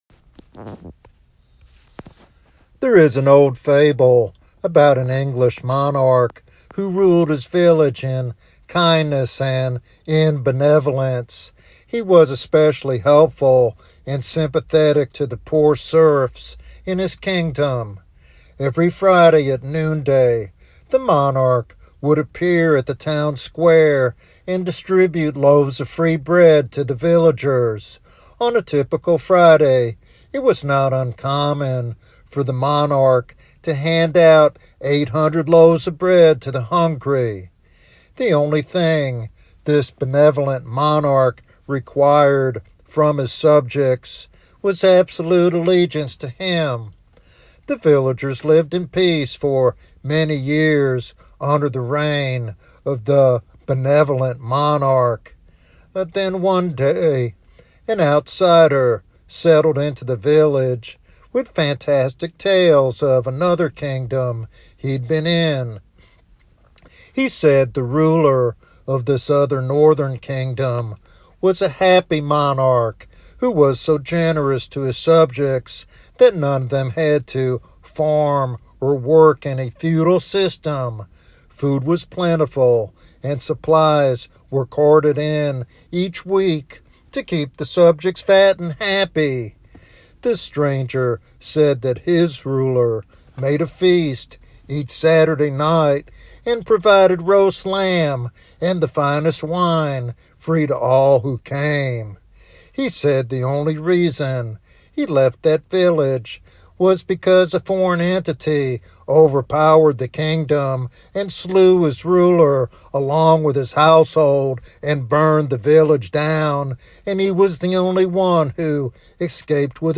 This evangelistic sermon invites all to come to Jesus for salvation and eternal life.
Sermon Outline